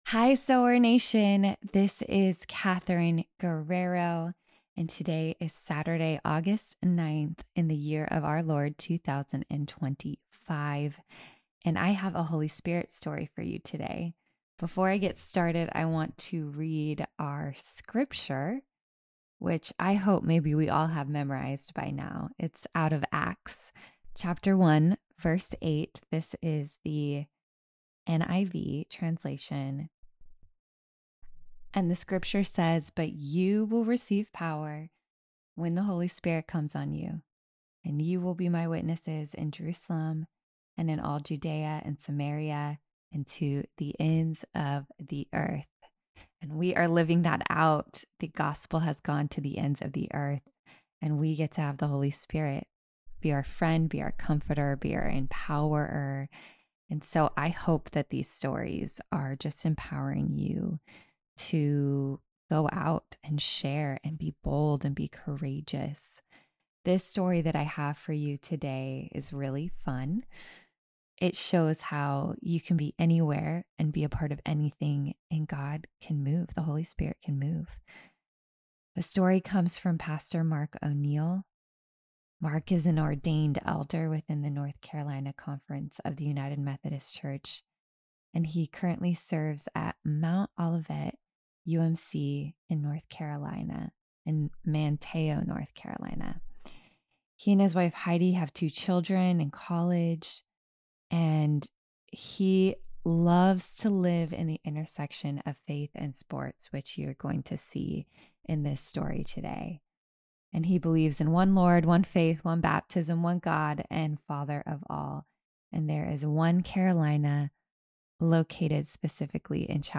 Below, you can read the story in his own words, and, if you listen, you will hear it in his own voice.